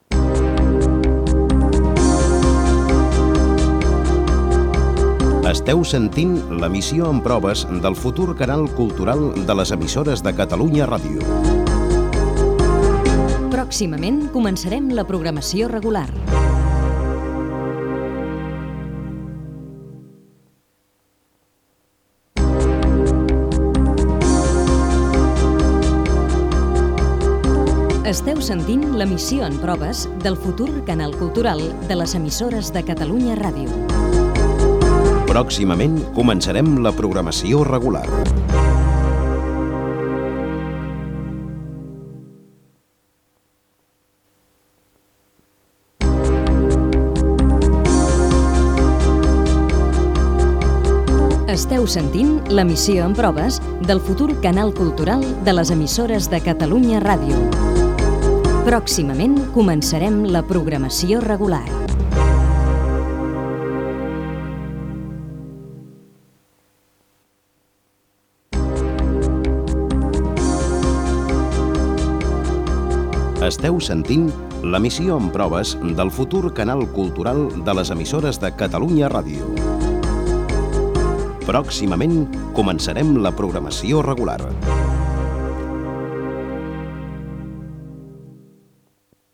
Emissió en proves. Missatge constant abans que s'iniciés la programació.
FM